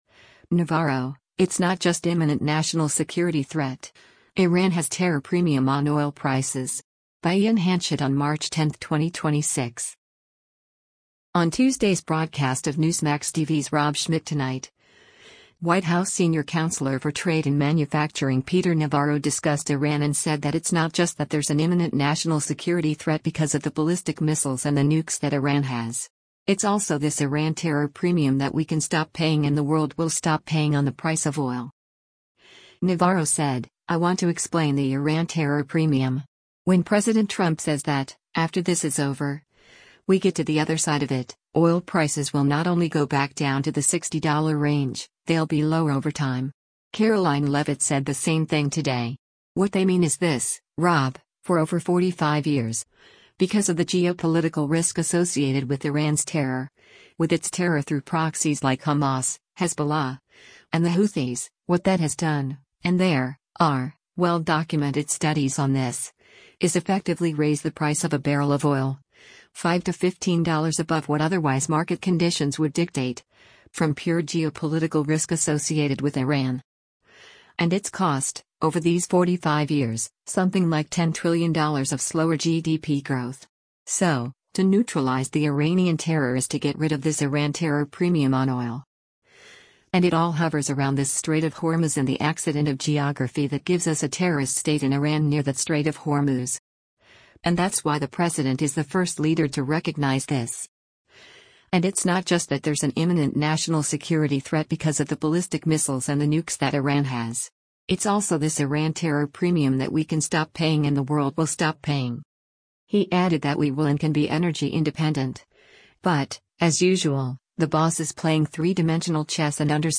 On Tuesday’s broadcast of Newsmax TV’s “Rob Schmitt Tonight,” White House Senior Counselor for Trade and Manufacturing Peter Navarro discussed Iran and said that “it’s not just that there’s an imminent national security threat because of the ballistic missiles and the nukes that Iran has. It’s also this Iran terror premium that we can stop paying and the world will stop paying” on the price of oil.